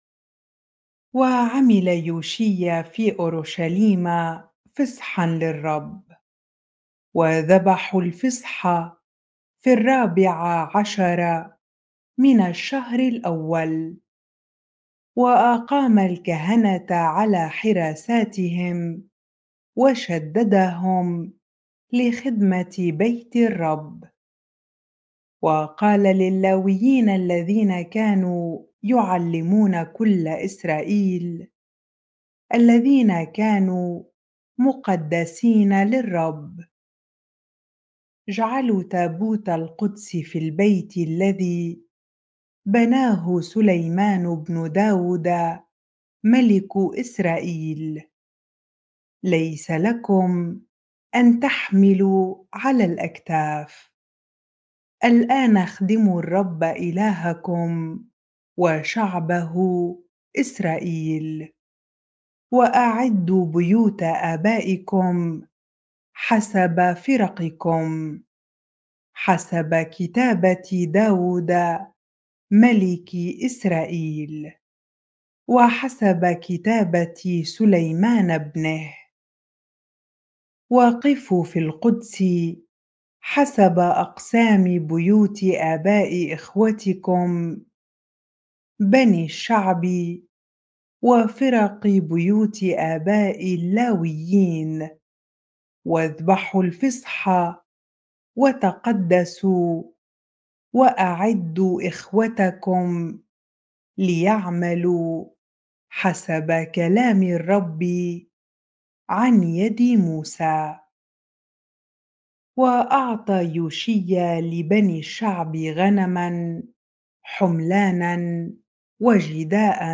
bible-reading-2 Chronicles 35 ar